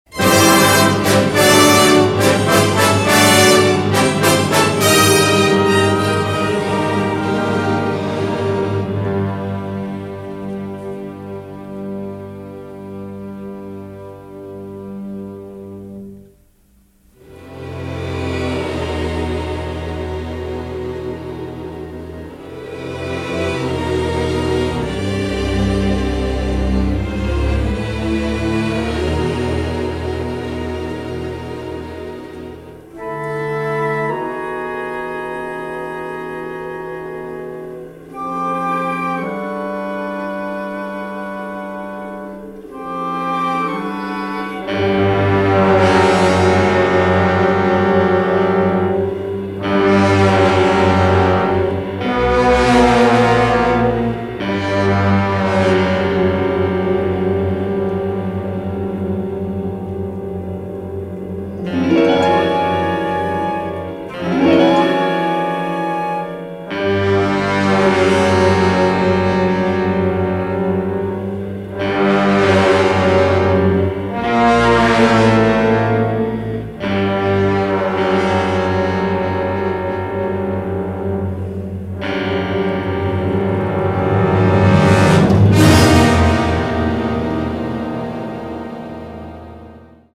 remarkable orchestral score